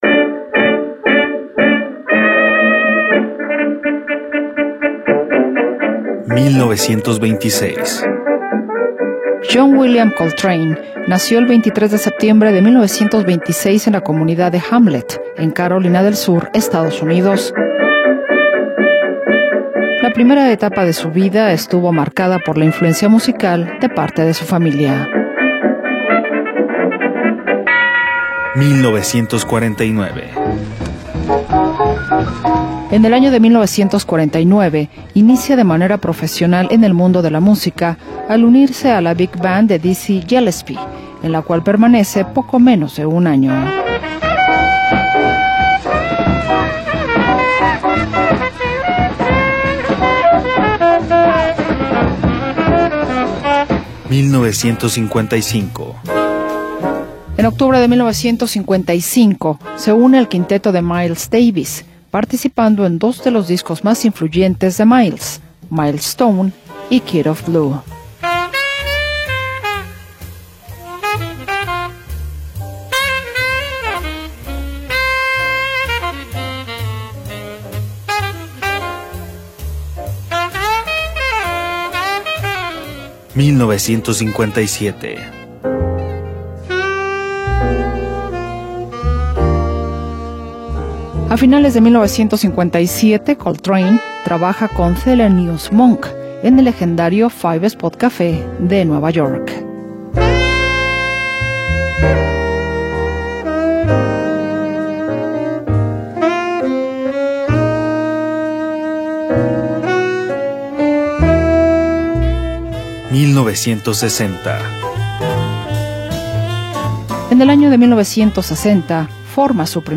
el disco es una suite espiritual
piano
contrabajo
batería